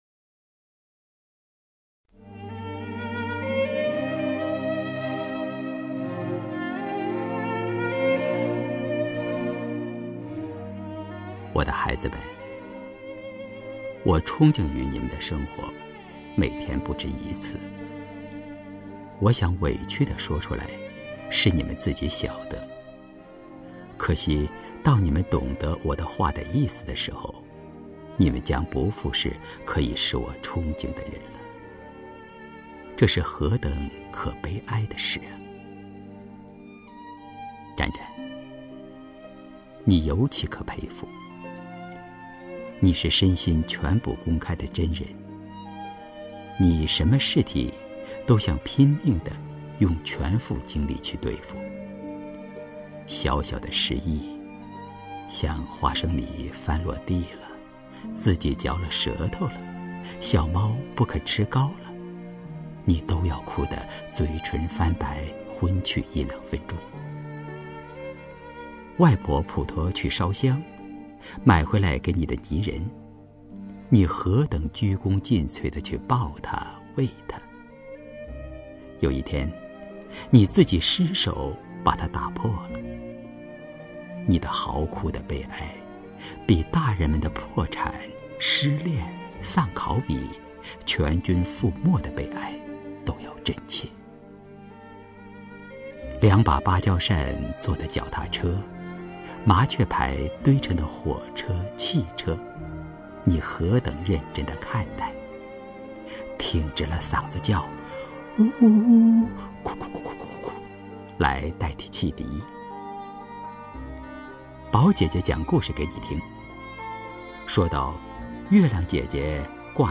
刘纪宏朗诵：《给我的孩子们》(丰子恺) 丰子恺 名家朗诵欣赏刘纪宏 语文PLUS